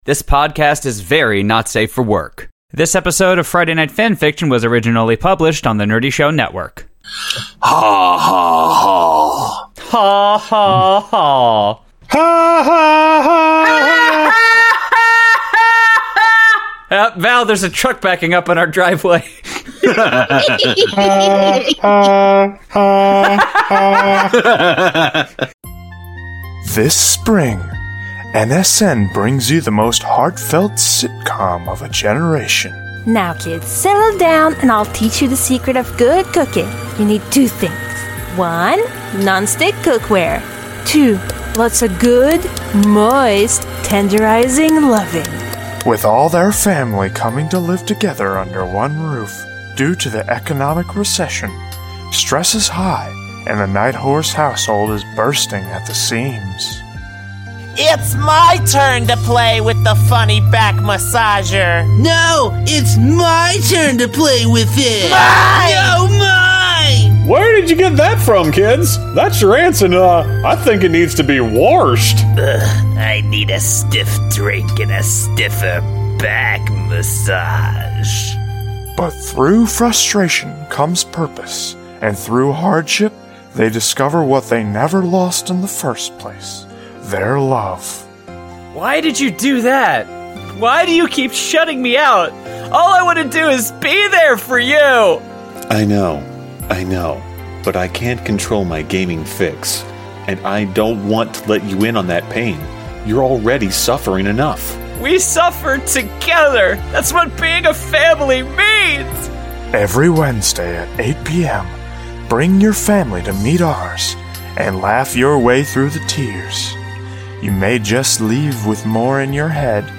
Fanfics read:My Inner Life (by Link’s Queen)The Hand You’re Dealt (by lacrimalis)